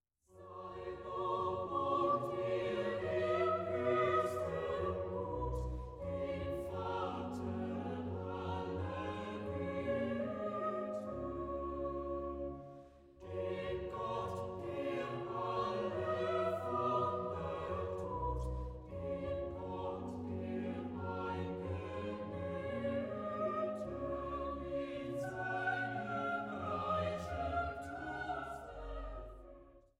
Schlosskirche Altenburg
Cembalo